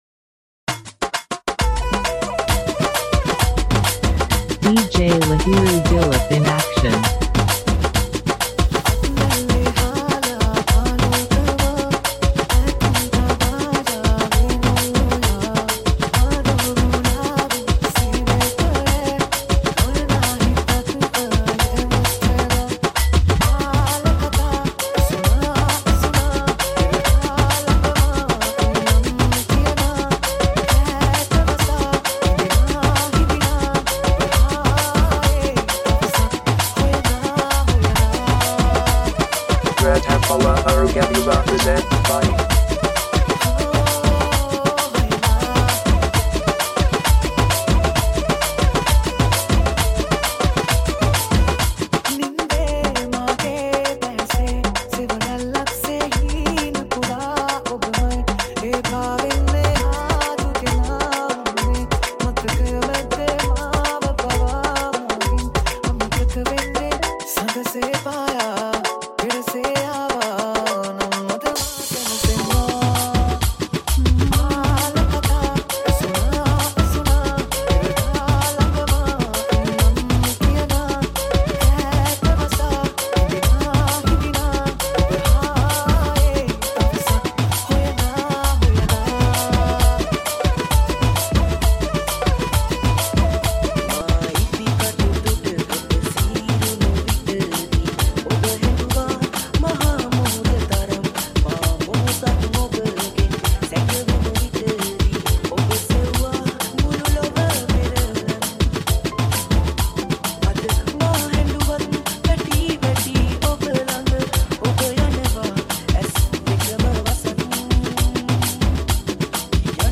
High quality Sri Lankan remix MP3 (6.1).